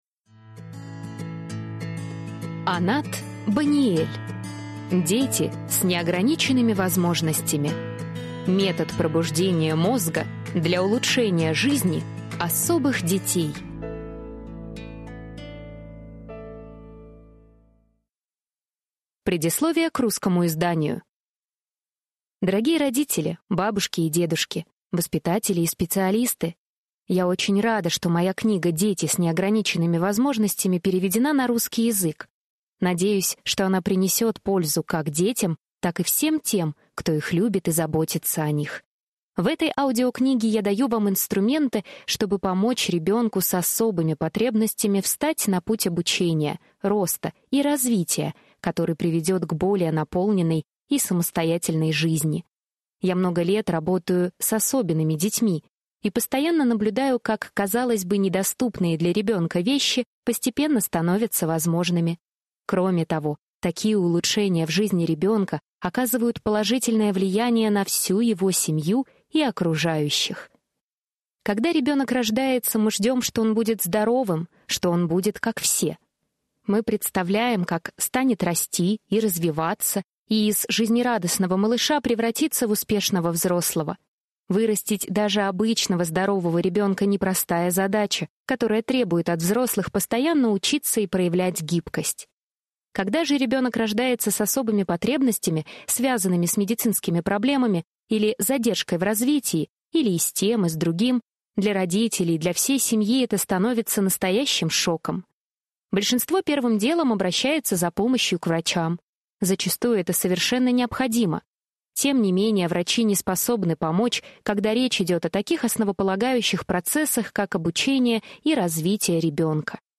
Аудиокнига Дети с неограниченными возможностями.